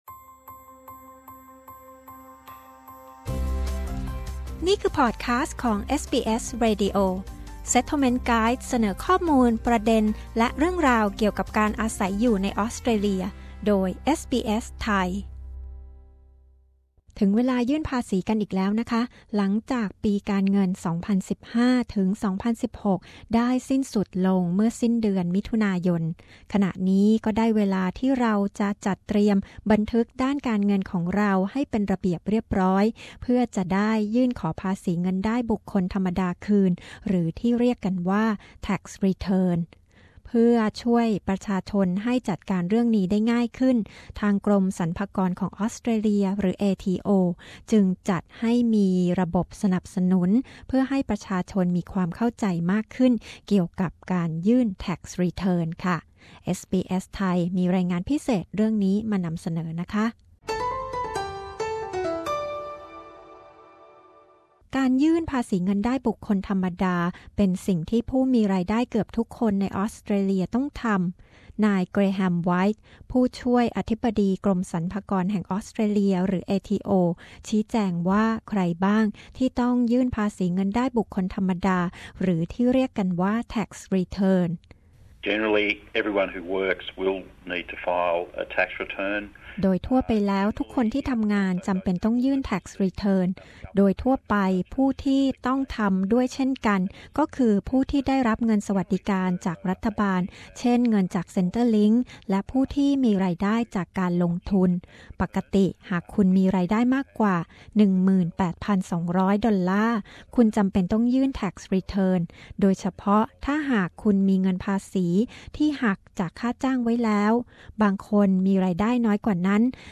ถึงเวลายื่่นภาษีเงินได้บุคคลธรรมดา หรือ Tax Return กันอีกแล้ว ใครบ้างต้องยื่น จะยื่นด้วยวิธีใดบ้าง ติดตามได้จากรายงานพิเศษของเอสบีเอส ไทย